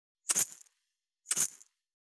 348岩塩を振る,調味料,カシャカシャ,ピンク岩塩,
効果音厨房/台所/レストラン/kitchen